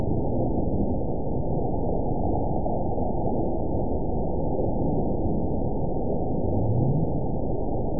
event 919907 date 01/28/24 time 04:23:04 GMT (1 year, 10 months ago) score 9.61 location TSS-AB01 detected by nrw target species NRW annotations +NRW Spectrogram: Frequency (kHz) vs. Time (s) audio not available .wav